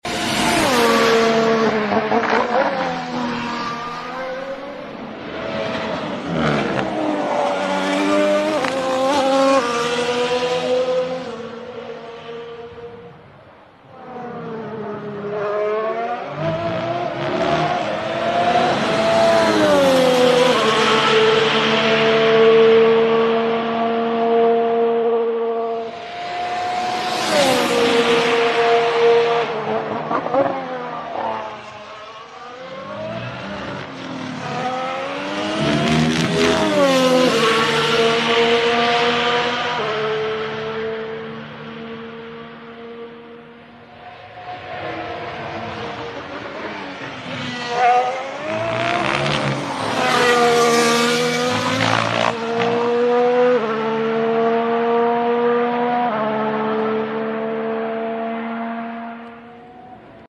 Sit and Listen to these Shifts 😍 Yuki Tsunoda AlphaTauri AT01 Pre-Season testing day at Imola